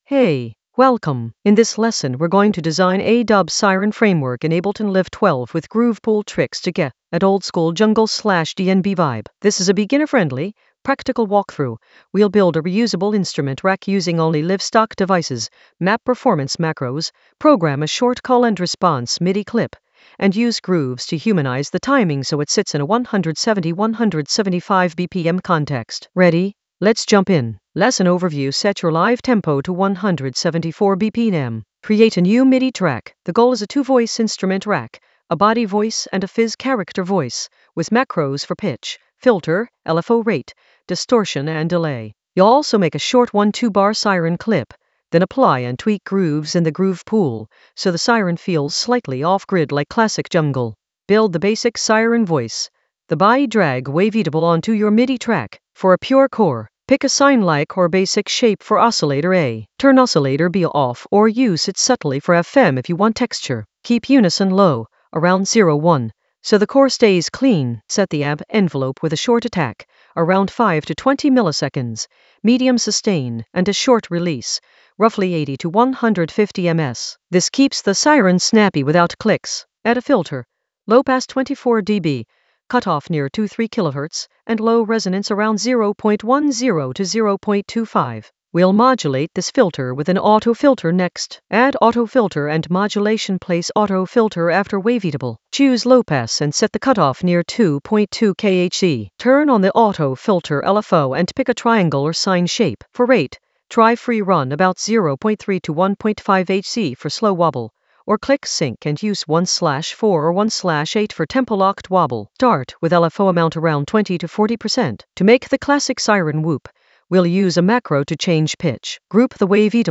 An AI-generated beginner Ableton lesson focused on Design a dub siren framework with groove pool tricks in Ableton Live 12 for jungle oldskool DnB vibes in the Sound Design area of drum and bass production.
Narrated lesson audio
The voice track includes the tutorial plus extra teacher commentary.